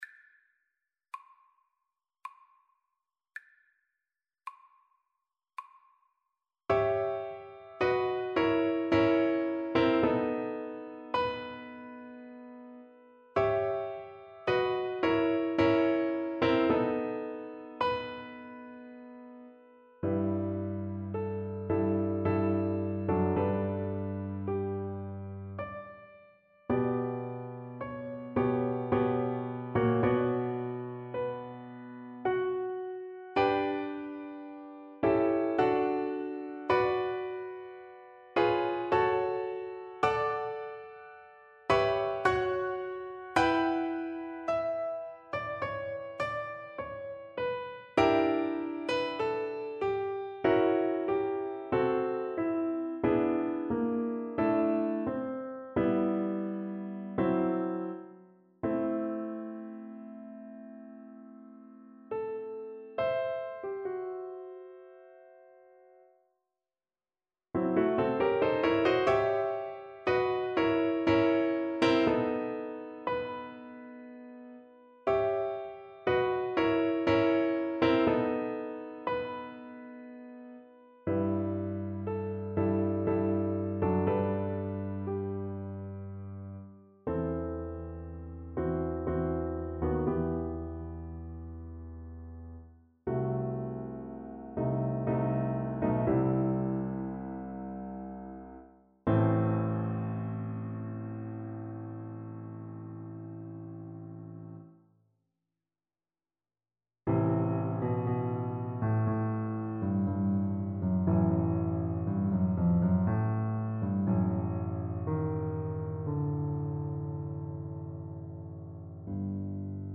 Soprano (Descant) Recorder version
Adagio lamentoso = 54
3/4 (View more 3/4 Music)
Classical (View more Classical Recorder Music)